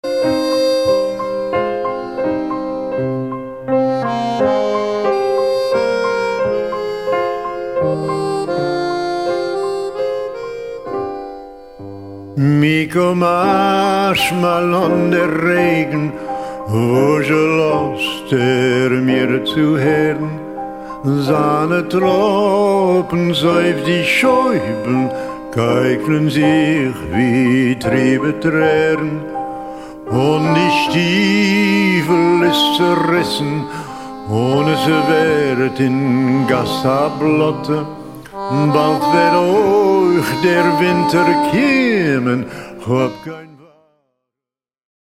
voce, narrazione
fisarmonica, pianoforte